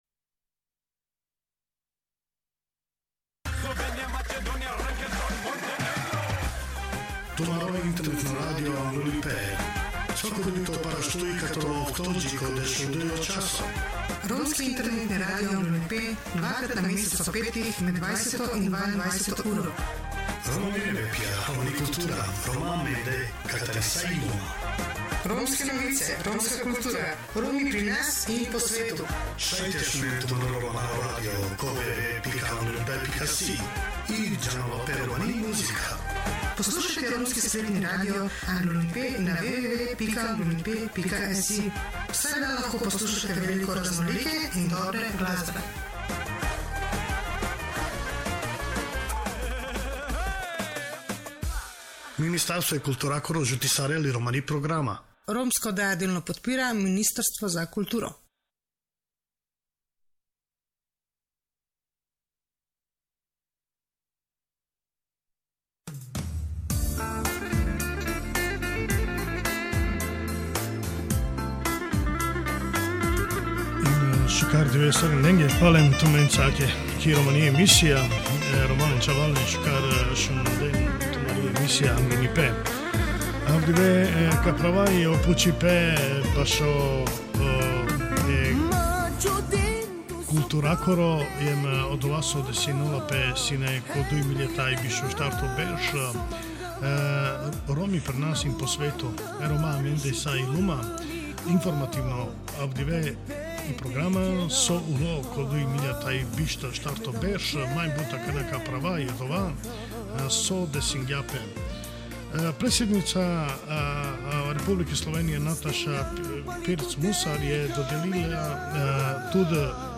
Drage poslušalke in dragi poslušalci, romska radijska oddaja Anglunipe, ki bo na sporedu 28. novembra ob 18.00 uri, se bo osredotočila na temo Romi pri nas in po svetu.